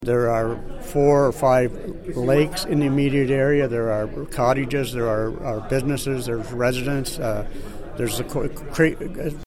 Warden Phillips says he intends to visit the site in the next couple of weeks.